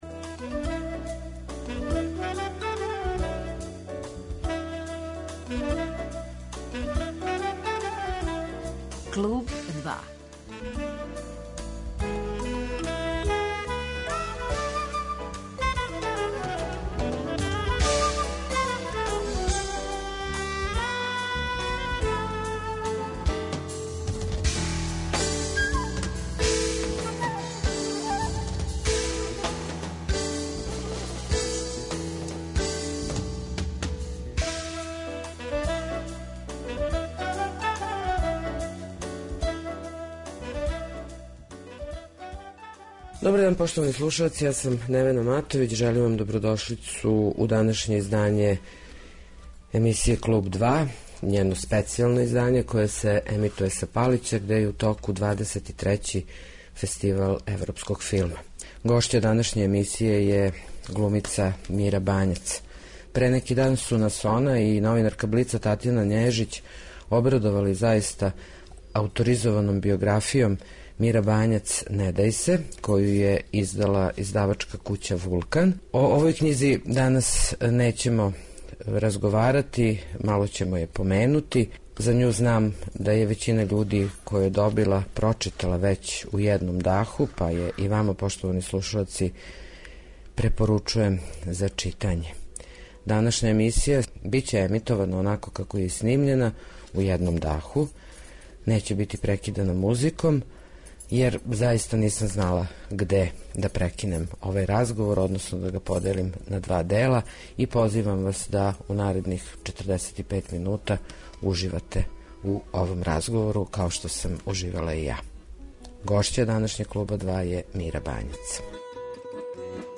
Гошћа је Мира Бањац